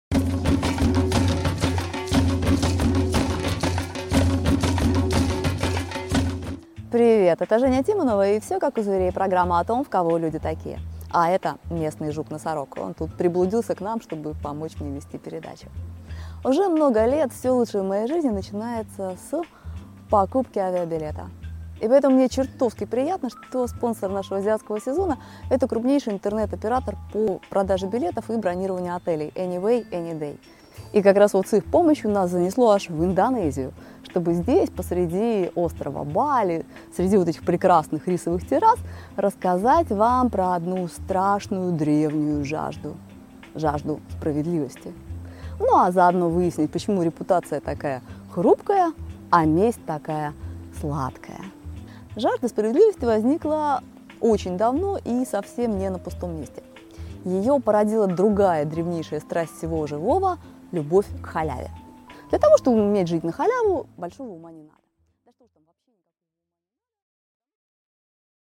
Аудиокнига Давайте по справедливости: альтруисты против халявщиков | Библиотека аудиокниг